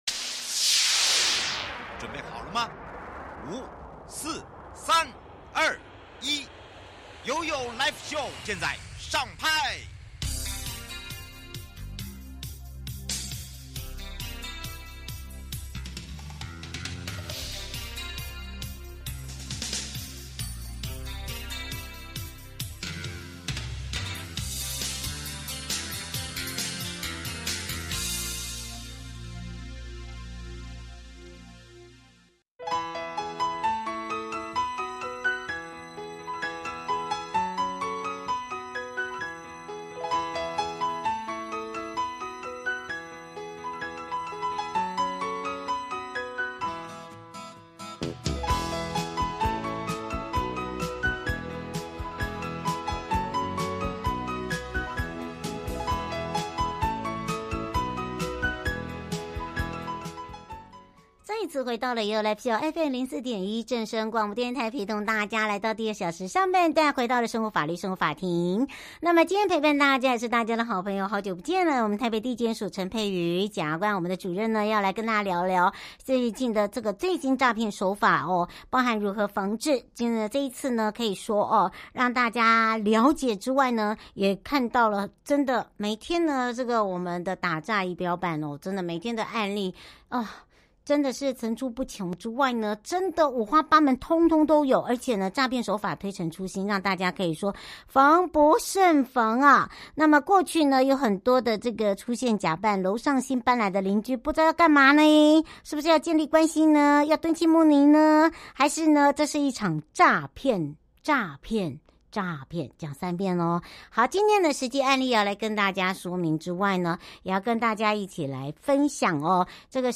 受訪者： 臺北地檢署 黃珮瑜檢察官 節目內容： 題目：最新詐騙手法簡介及防治！